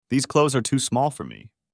Portanto, a pronúncia será com som de /z/, ou seja, /klouz/.